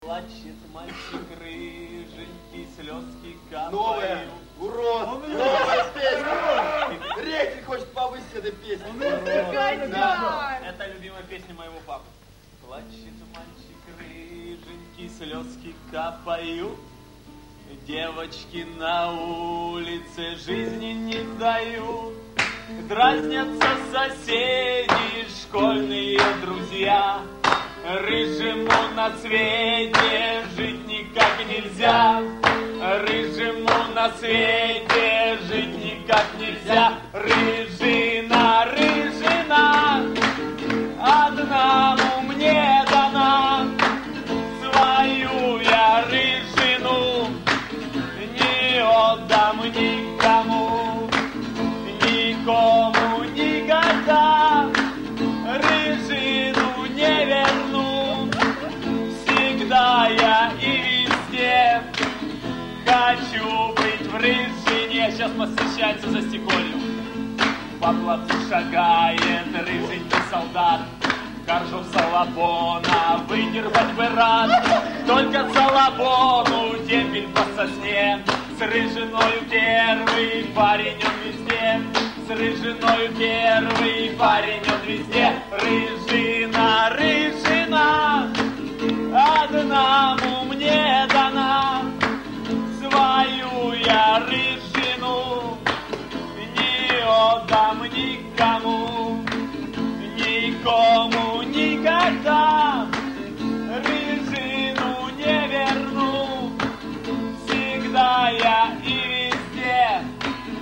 К сожалению у них плохое качество записи, но лучше нету. :-(